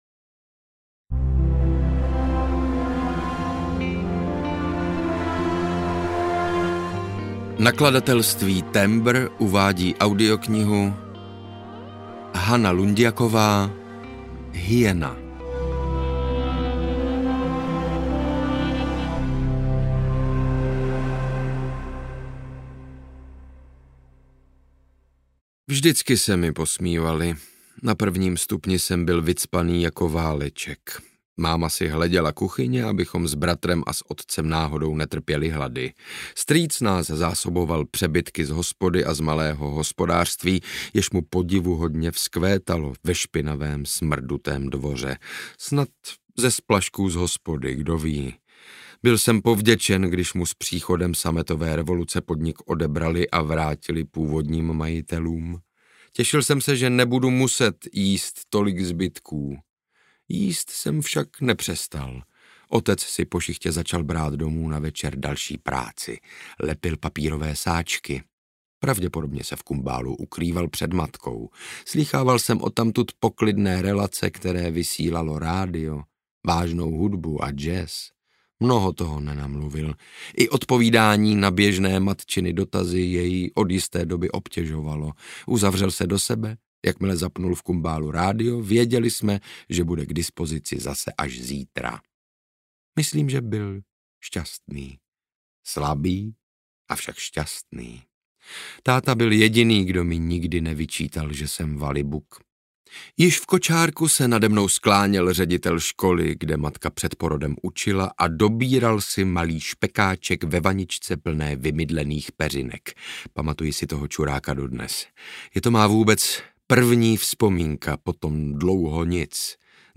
Hyena audiokniha
Ukázka z knihy
• InterpretSaša Rašilov